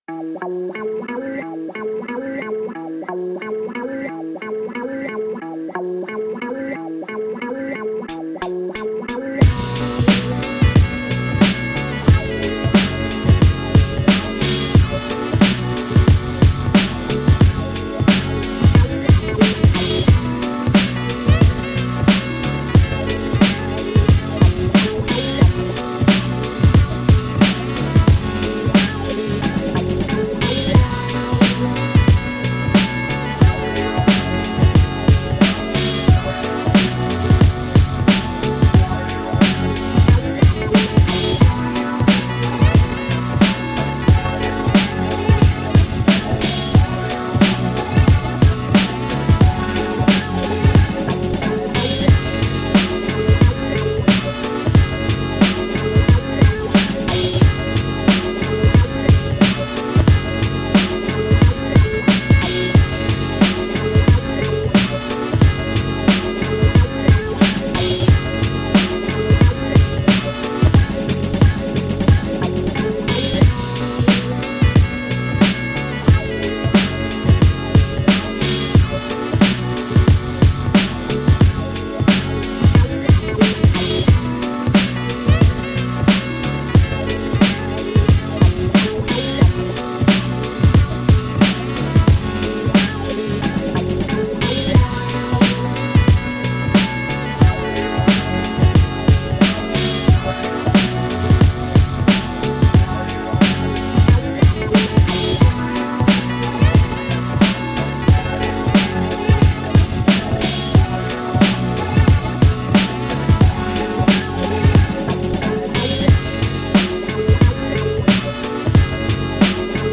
Boom Bap Instrumentals